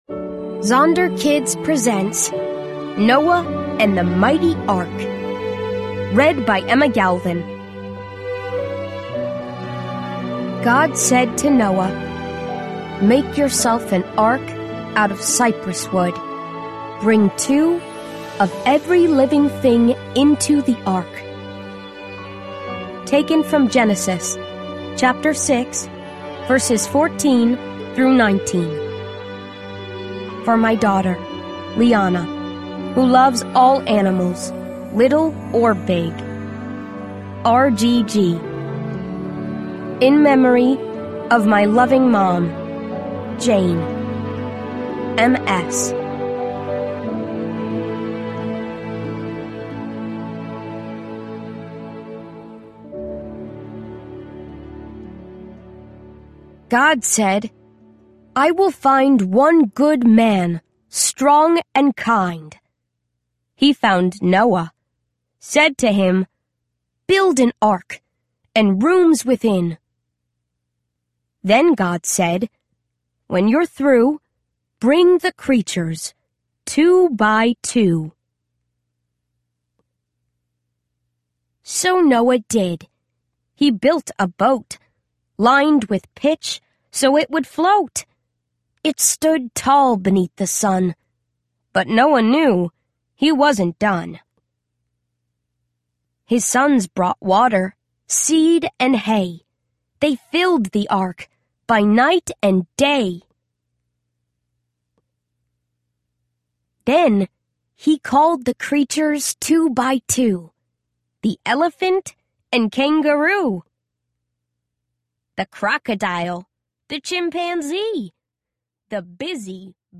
Rhonda Gowler Greene Children’s Collection Audiobook
Narrator